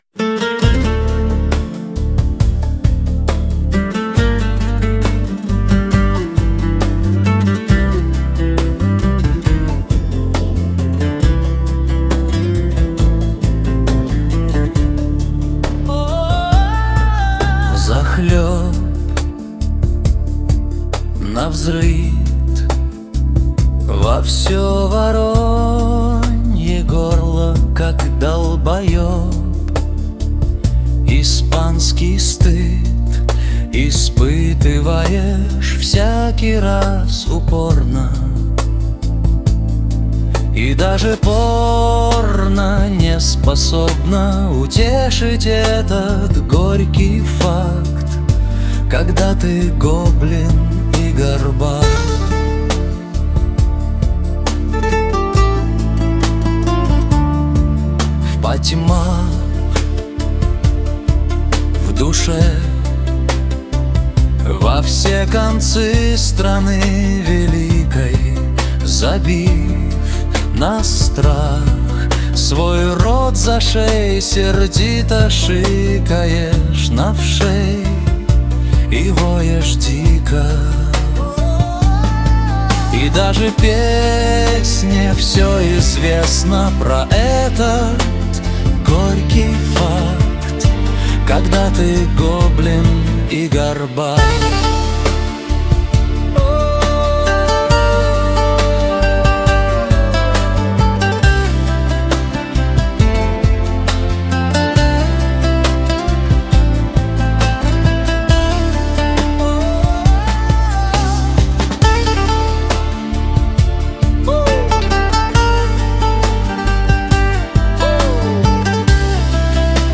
Один - мультиинструменталист, другой - бас и ударные.
mp3,6818k] Комедия